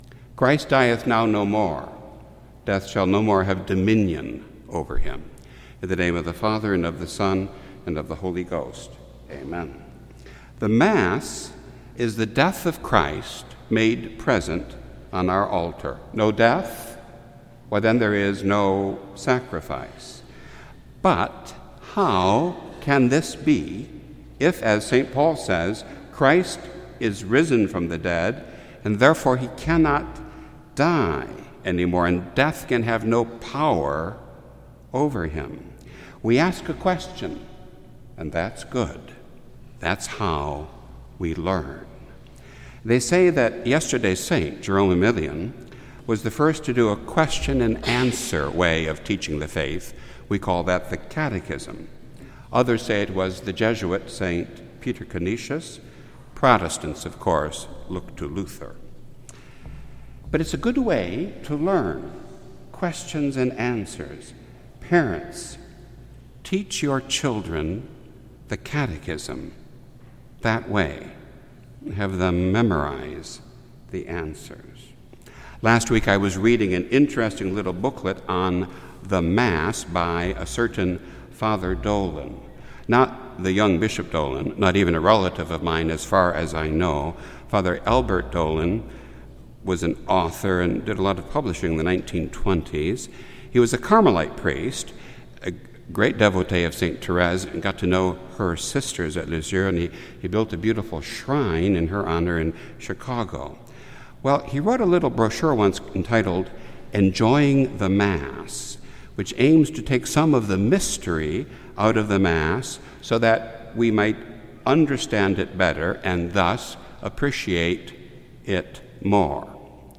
This entry was posted on Sunday, July 21st, 2019 at 5:22 pm and is filed under Sermons.